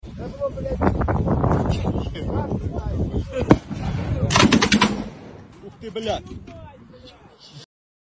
기관총 반동 제어에 실패한 우크라이나군 sound effects free download